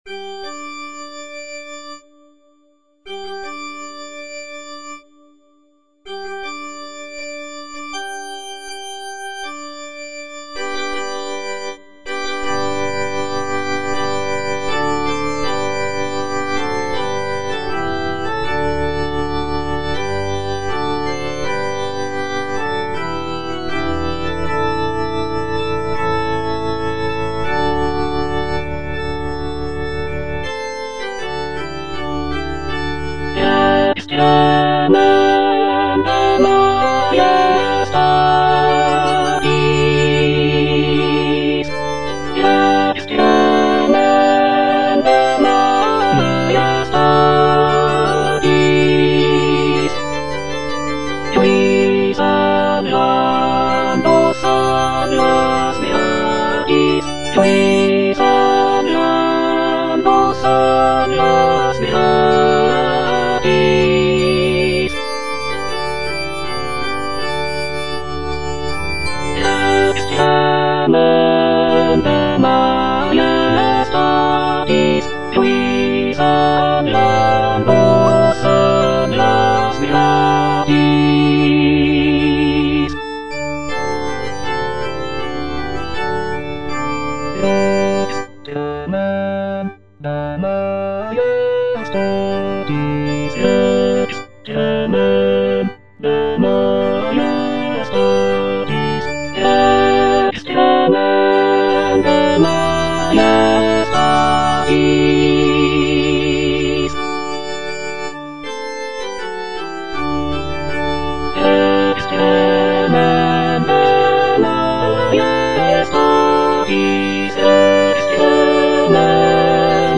(alto I) (Emphasised voice and other voices) Ads stop
is a sacred choral work rooted in his Christian faith.